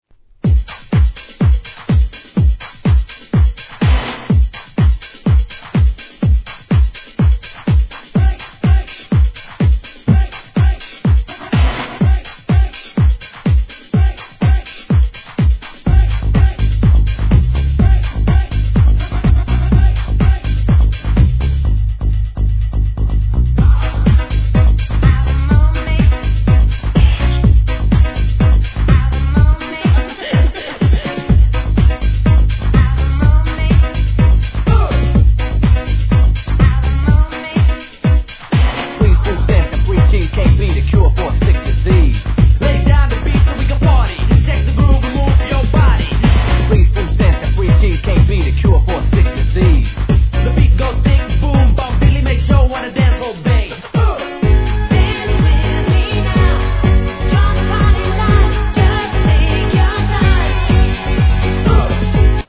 派手なシンセ・アタックといなたいRAP&女性ヴォーカルがたまらないイタロ・ディスコ〜HIP HOUSE!